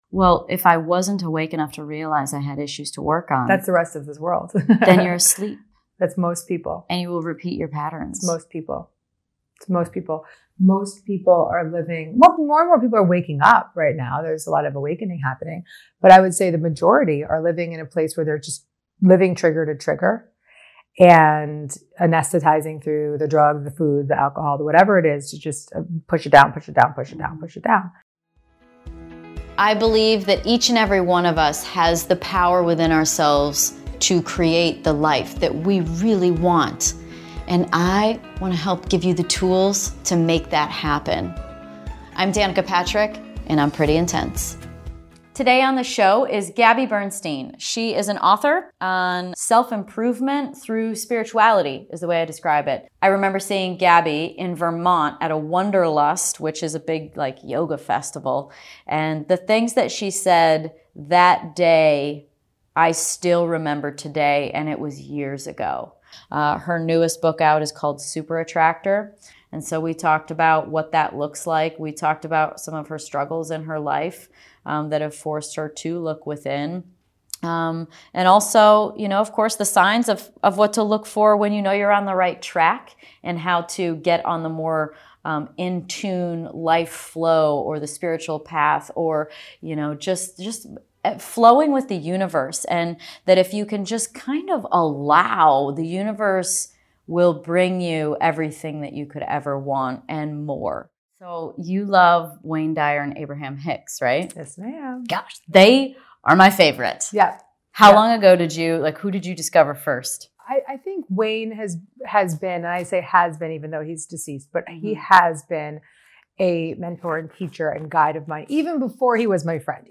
Please enjoy this very intimate and profound chat with Danica Patrick and Gabby Bernstein.